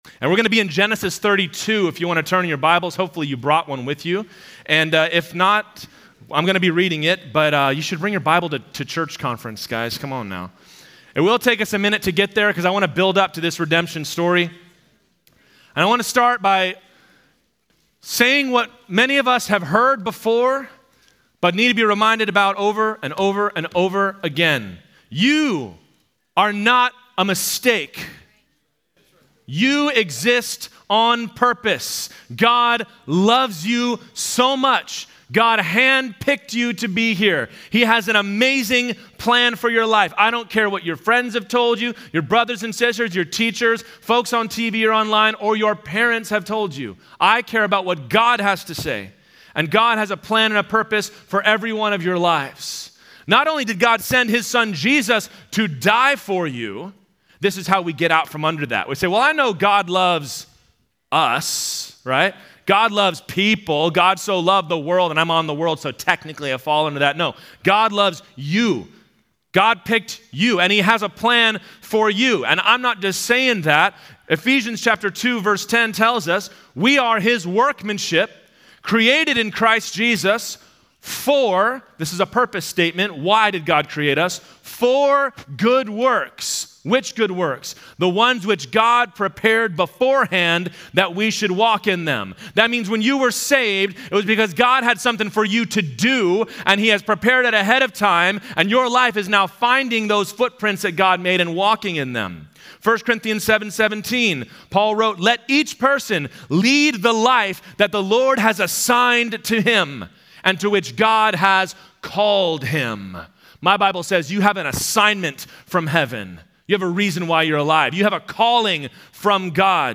Home » Sermons » Redemption Stories: Jacob